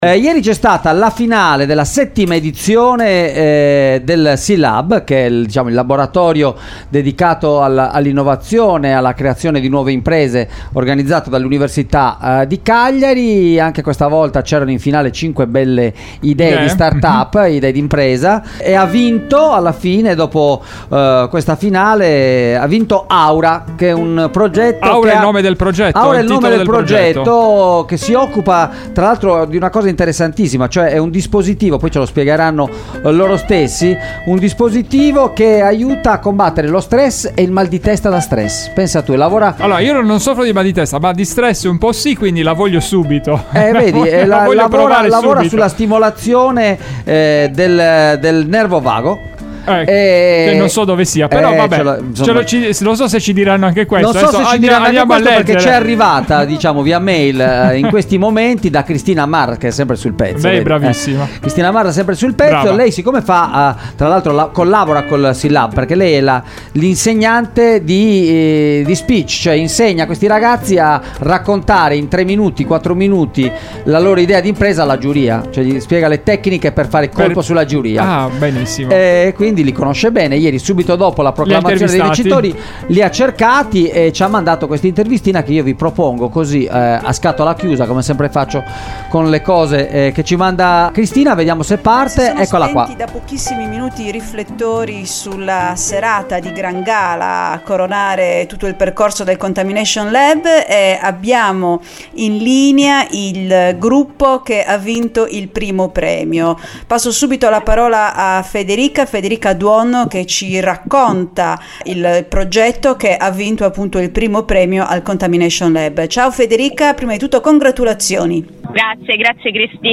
è andata a intervistare i ragazzi del team vincitore